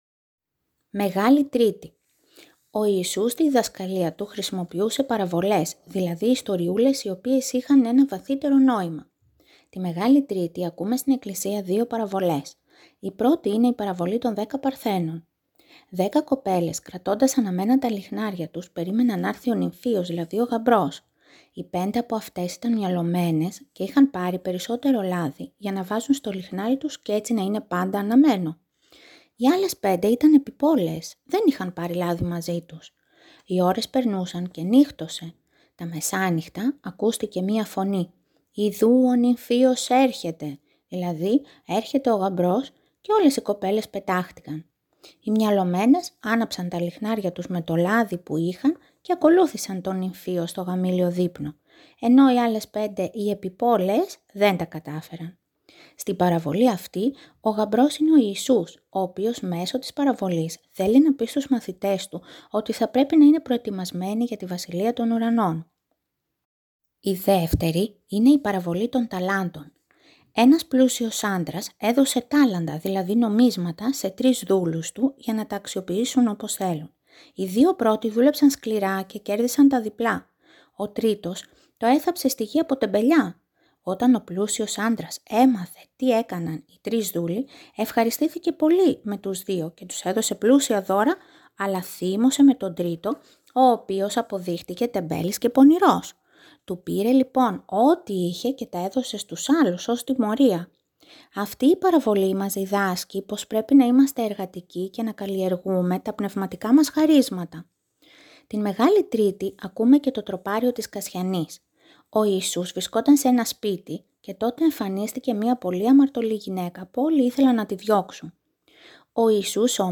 Τέλος στον εσπερινό της Μεγάλης Τρίτης ακούγεται ένα από τα ωραιότερα τροπάρια της εκκλησιαστικής υμνογραφίας: Το τροπάριο της Κασιανής.